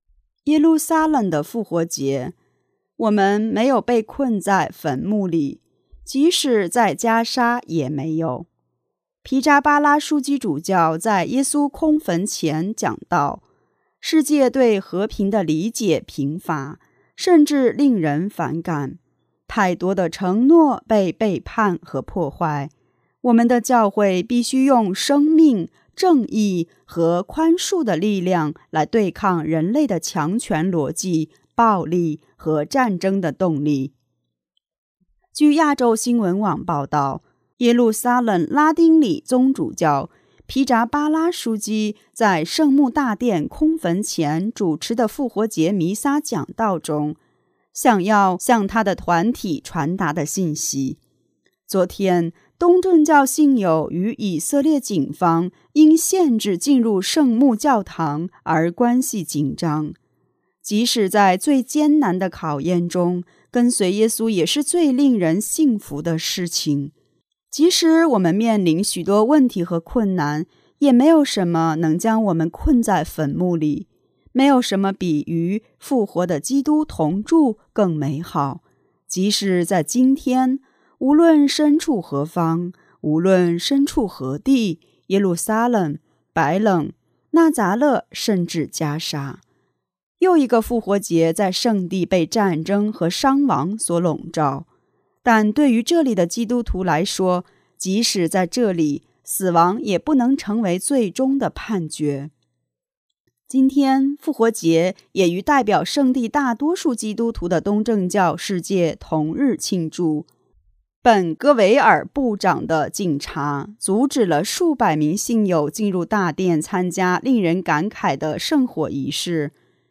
皮扎巴拉枢机主教在耶稣空墓前讲道。世界对和平的理解贫乏，甚至令人反感，太多的承诺被背叛和破坏。